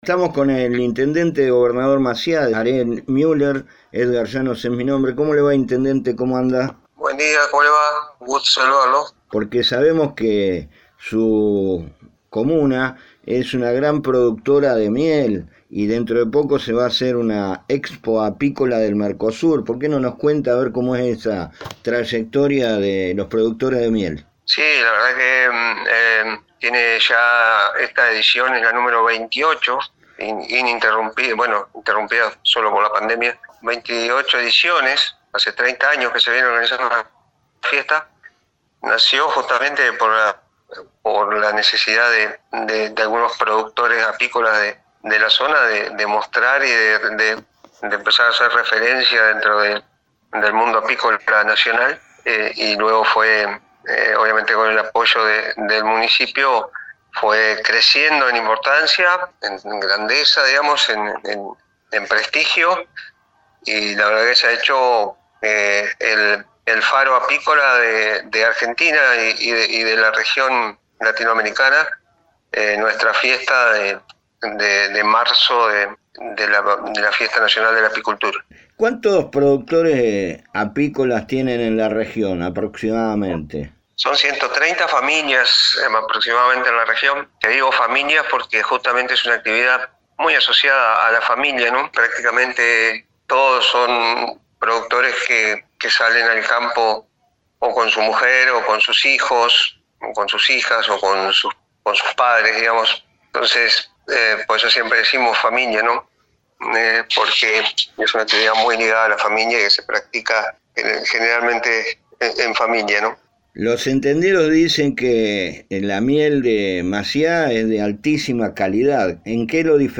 La ciudad entrerriana de Gobernador Maciá, está ubicada en el centro de la provincia y pertenece al Departamento Tala. Es una de las regiones apícolas más importantes de nuestro país, y en pocos días se realiza la edición 28 de la Expo Apícola del Mercosur, por este motivo A1 Noticias entrevistó al intendente Ariel Müller
Ariel-Muller-Int-Macia-Expo-Apicola.mp3